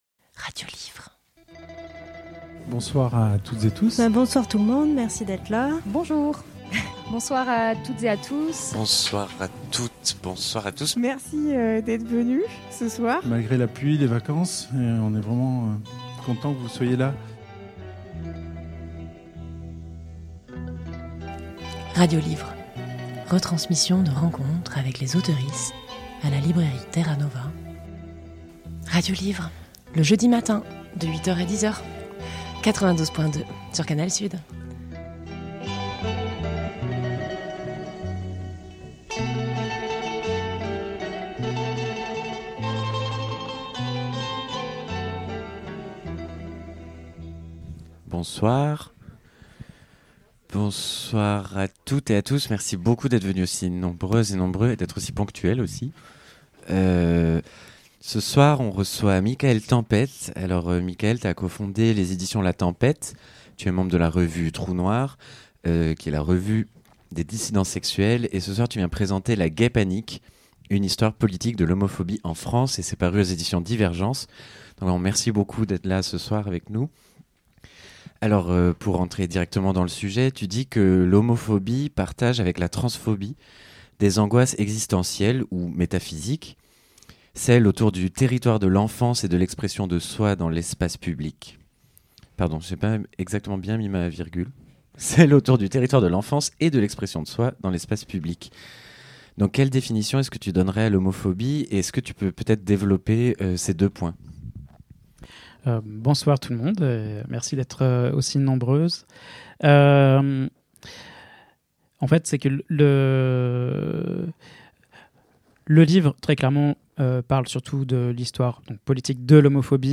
Le 18 octobre 2023 à la librairie Terra Nova